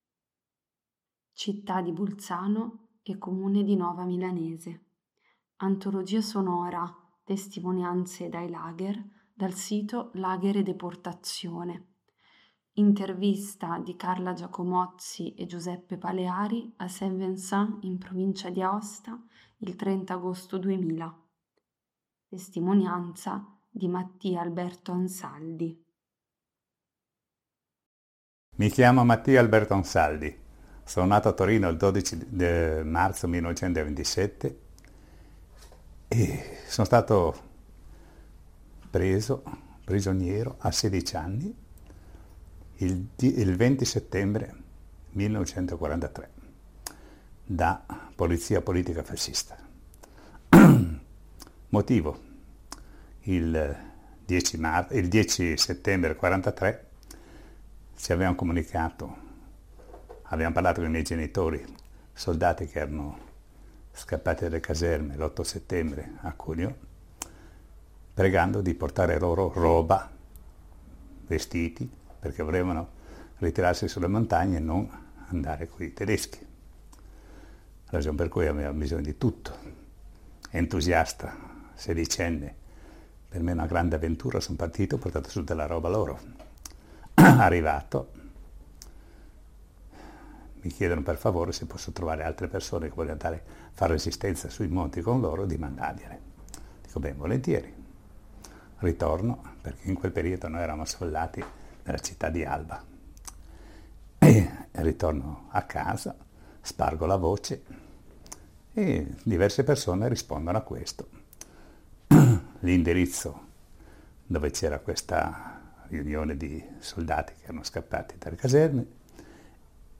Intervista del 30/08/2000 a Saint Vincent